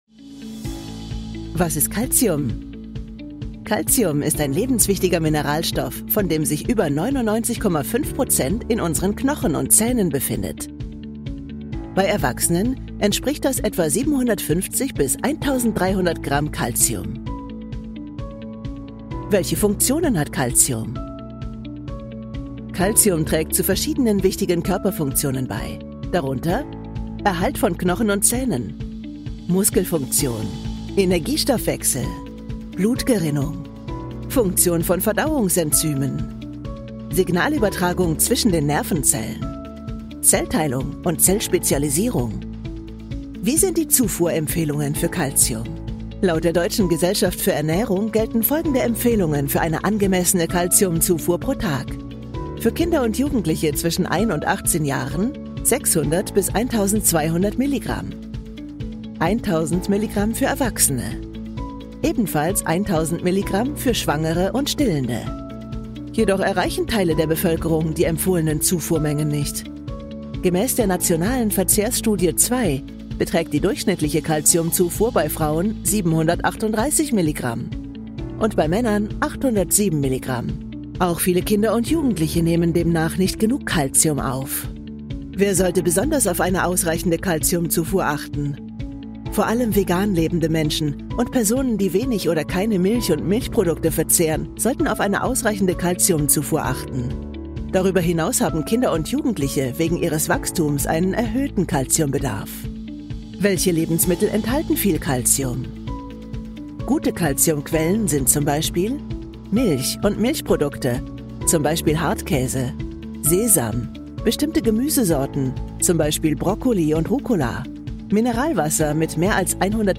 dunkel, sonor, souverän, markant, sehr variabel
Mittel plus (35-65)
Berlinerisch, Sächsisch
Demoreel
Commercial (Werbung)